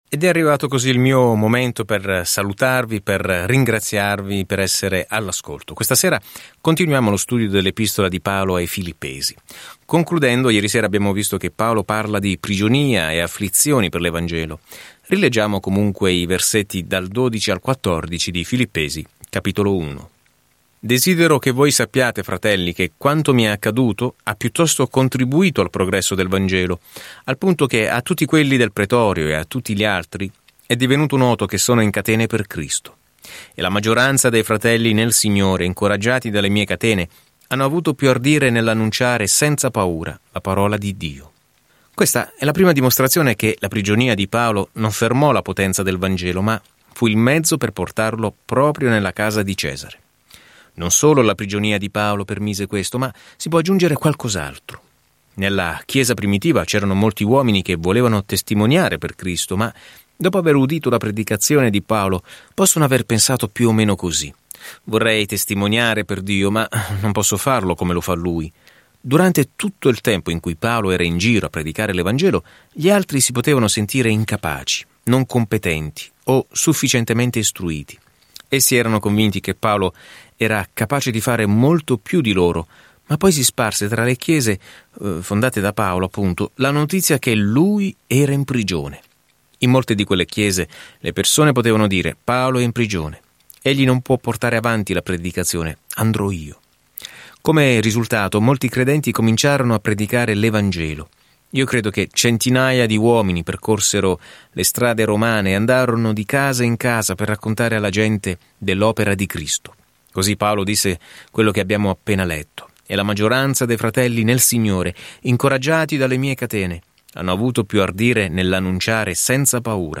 Scrittura Lettera ai Filippesi 1:12-30 Giorno 2 Inizia questo Piano Giorno 4 Riguardo questo Piano Questa nota di “grazie” ai Filippesi offre loro una prospettiva gioiosa dei momenti difficili in cui si trovano e li incoraggia ad affrontarli umilmente insieme. Viaggia ogni giorno attraverso Filippesi mentre ascolti lo studio audio e leggi versetti selezionati della parola di Dio.